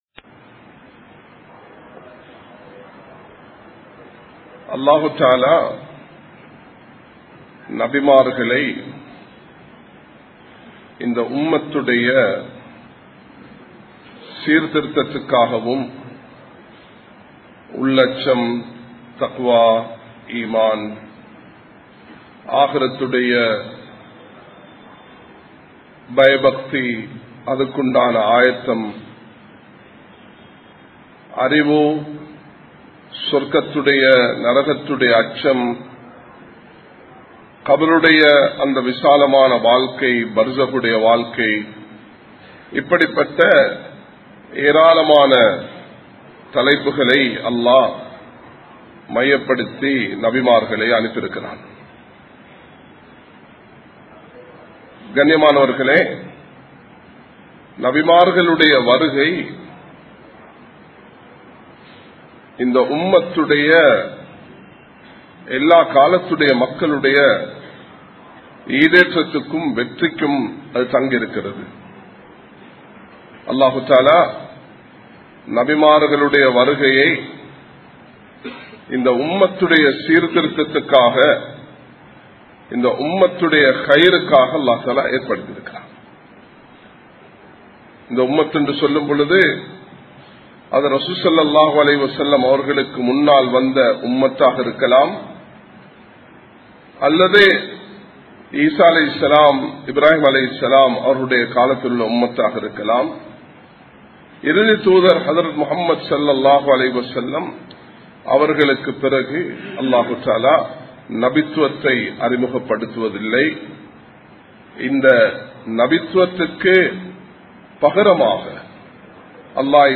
Muslim Samoohaththai Paathuhaarungal (முஸ்லிம் சமூகத்தை பாதுகாருங்கள்) | Audio Bayans | All Ceylon Muslim Youth Community | Addalaichenai
Colombo 11, Samman Kottu Jumua Masjith (Red Masjith)